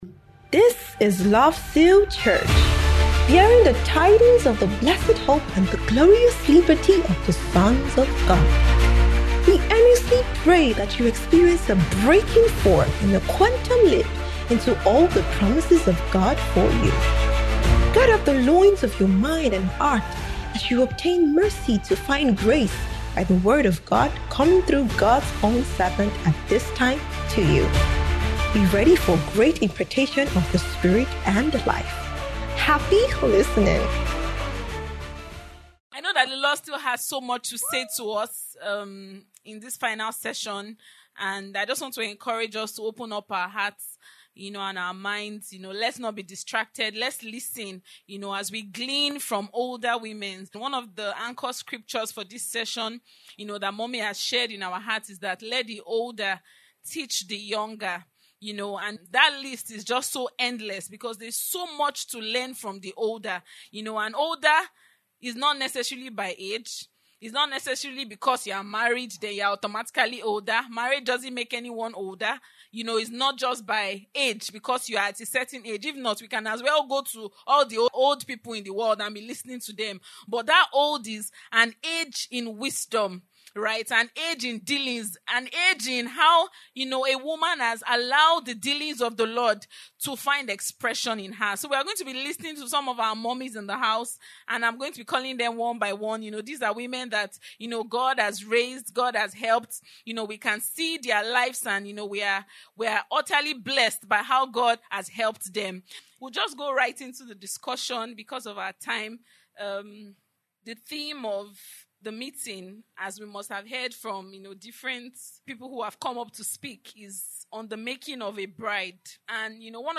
Married Women Panel Session
by Panelists on 14th March 2026